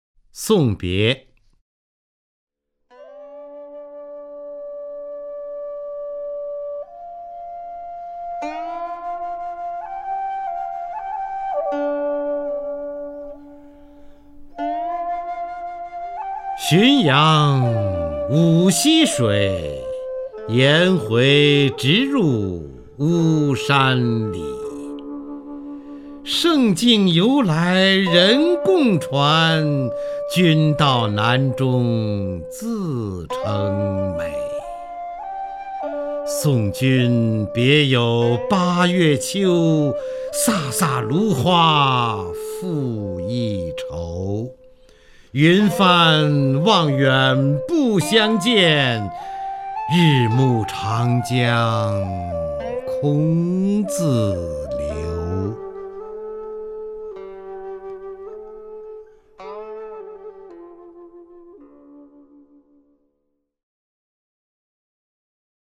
方明朗诵：《送别》(（唐）李白) （唐）李白 名家朗诵欣赏方明 语文PLUS